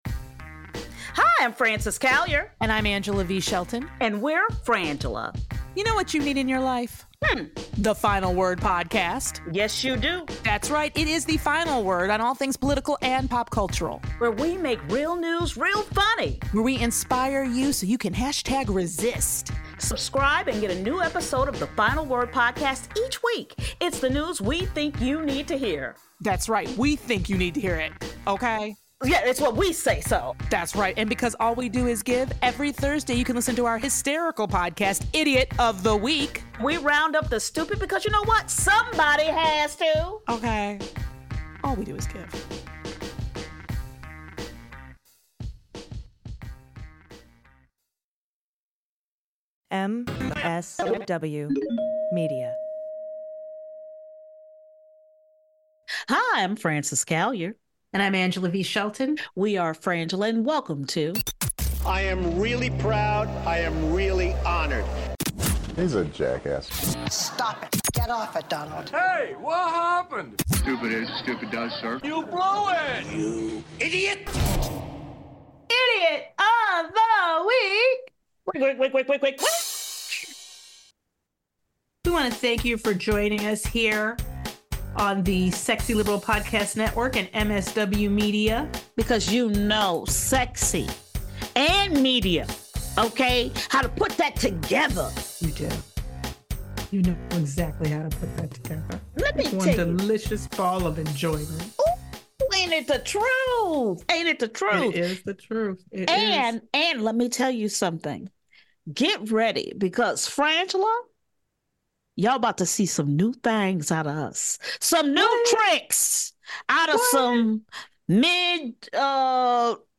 Society & Culture, News, Comedy